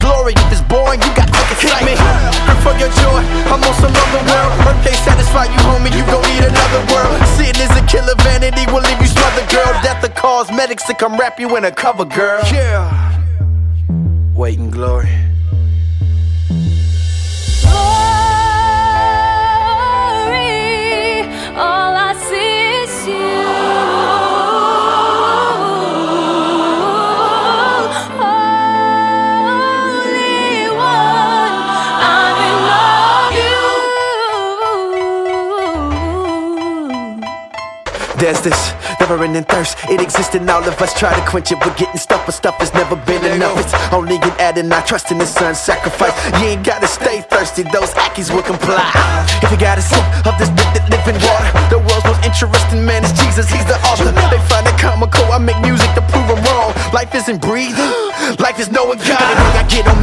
deren Sound von Urban-Beats geprägt ist.
• Sachgebiet: Rap & HipHop